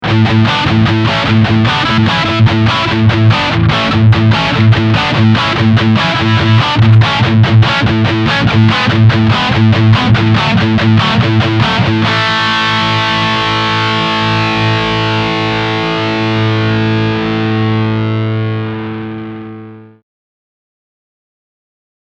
Die Röhrendynamik macht sich im Spielgefühl sowie im Klang deutlich bemerkbar und beschert eine sehr ausgewogene Verzerrung, die deutlich in Richtung der großen US-Amps geht.
Eine sehr klare Definition, die selbst bei angeschlagenen Akkorden jeden Ton zur Geltung bringt, sowie eine cremige Mittenfärbung des zweiten Kanals ergeben eine fantastische Kombination.
Kanal B | Moderates Gain
two_notes_le_lead_testbericht_kanal_b_medium_gain.mp3